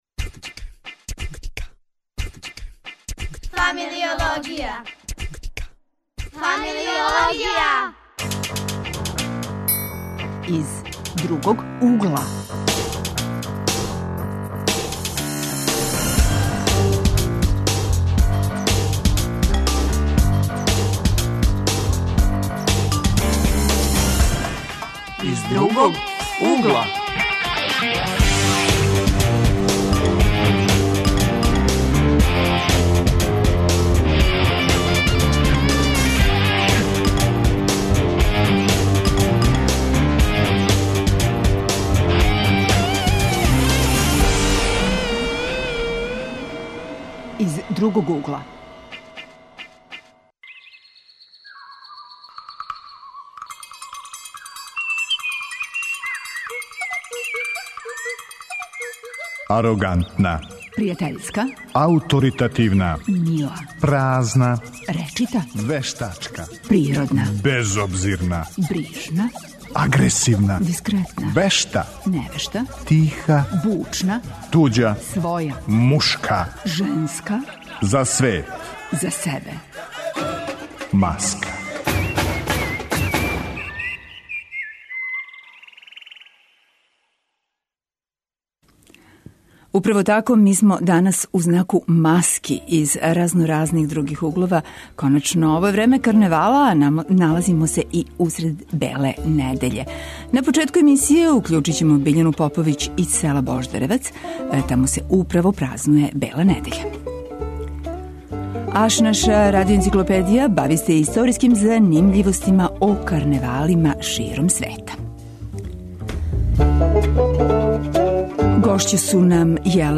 Тема нашег разговора биће маске које користимо у свакодневној комуникацији.